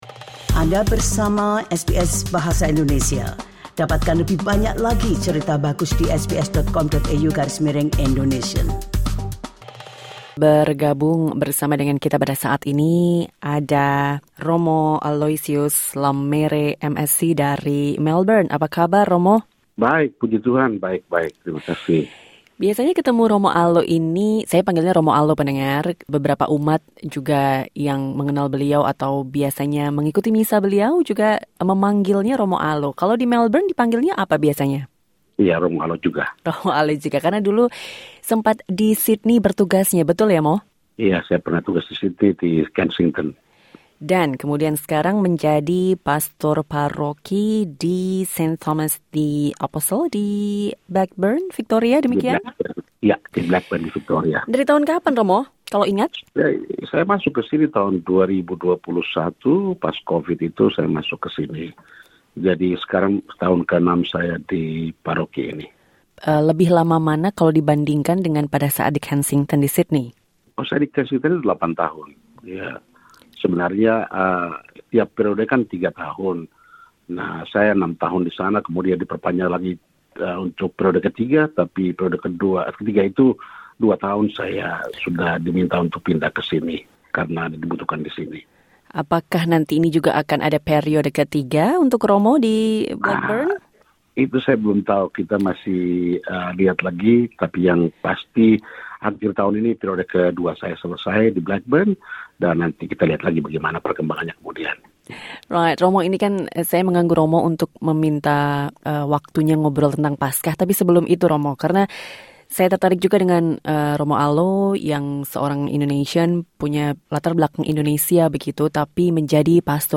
Also listen Raising gifted children with Indonesian culture in Australia SBS Indonesian 25:18 Indonesian Listen to the full interview.